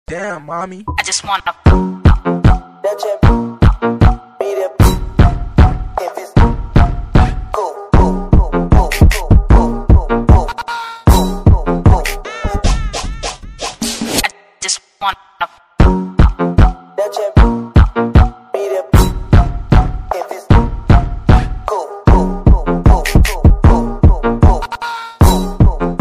• Качество: 128, Stereo
ритмичные
громкие
dance